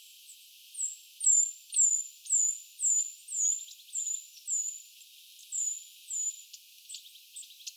onko tämä vastaava ääntely
kuin tavallisella puukiipijällä sirittely?
ti-puukiipija_innokkaampi_versio_aantelysta.mp3